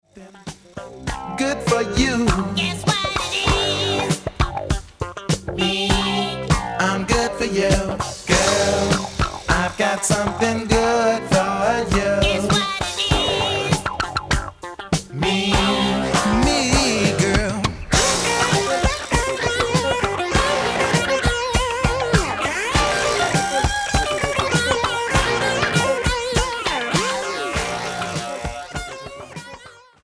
Genere:   Soul Funky